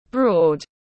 Rất rộng tiếng anh gọi là broad, phiên âm tiếng anh đọc là /brɔːd/ .